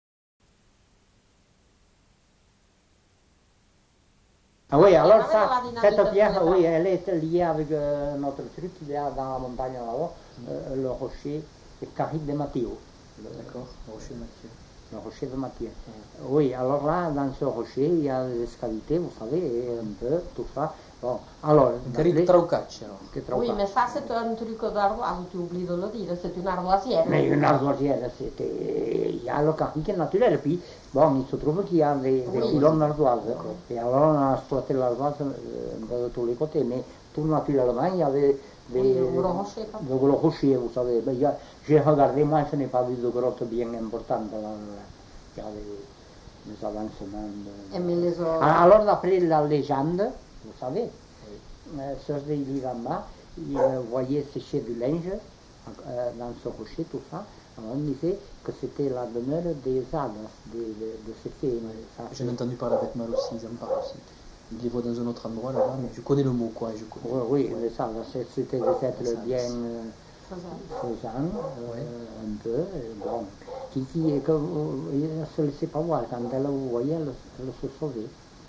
Lieu : Eylie (lieu-dit)
Genre : conte-légende-récit
Type de voix : voix d'homme
Production du son : parlé
Classification : récit légendaire